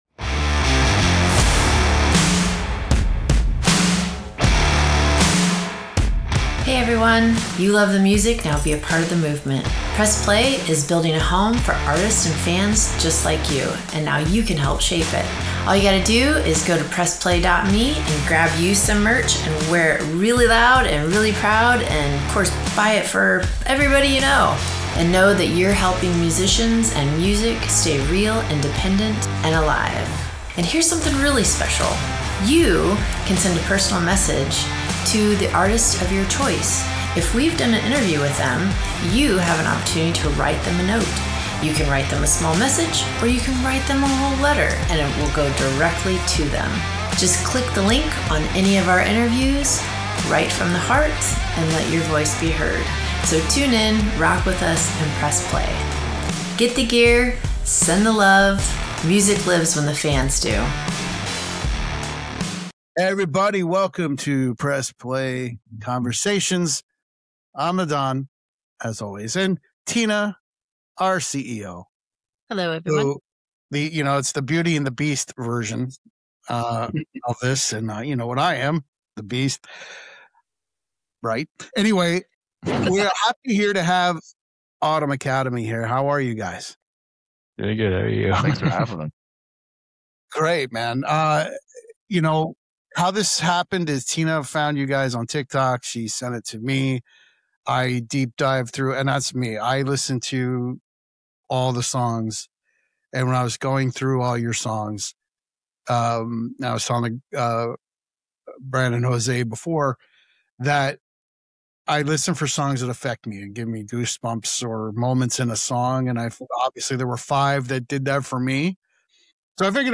From raw songwriting and standout tracks to an unfiltered conversation about AI in music, this Press Play Conversation dives into what it actually means to create in 2026.